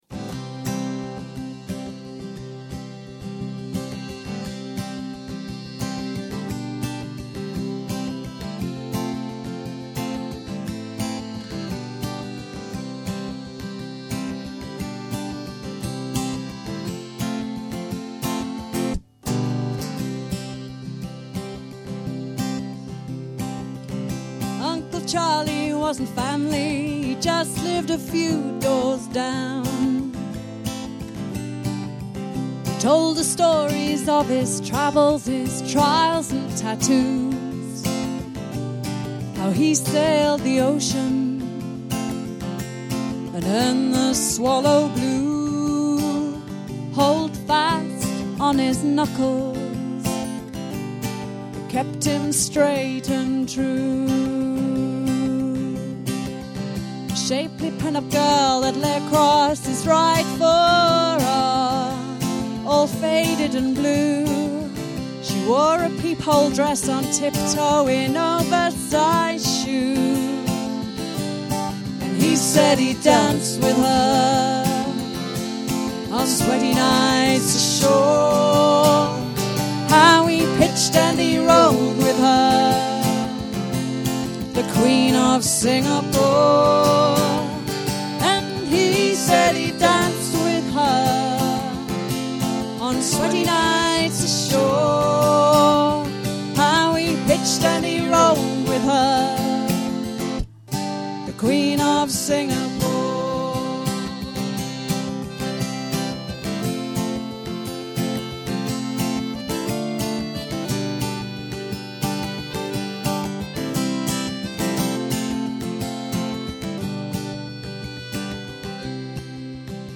Ashington Folk Club - 01 February 2007